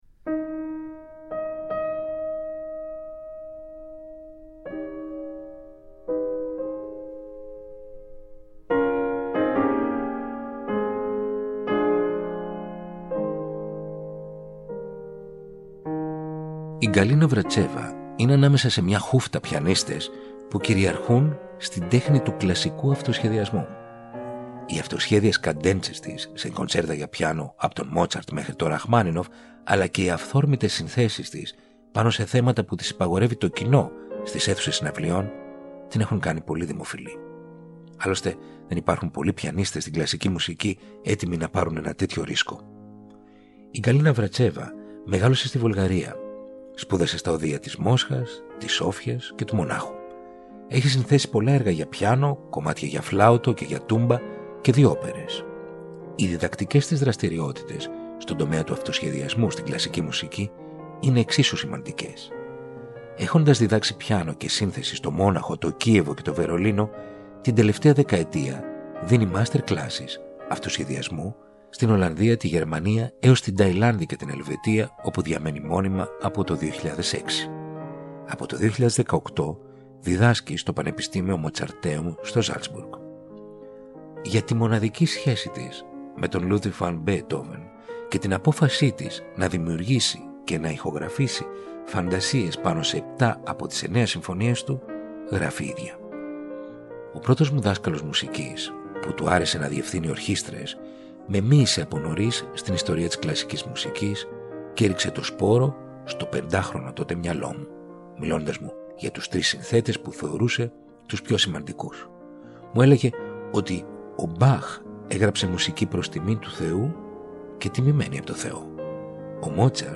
Δύο Φαντασίες για σόλο πιάνο
σε μια πολύ ιδιαίτερη τζαζ ερμηνεία